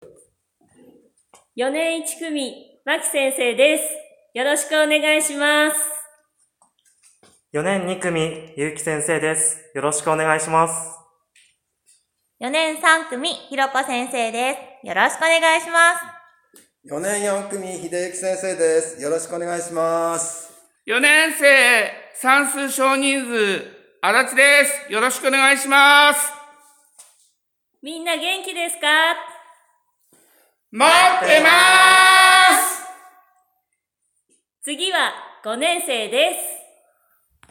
４年生の先生たちからのメッセージ
[ プレイヤーが見えない場合はこちらをクリック ] 先生方の自己紹介です。 早く学校が始まって，みんなに会える日がくることを楽しみに待っています。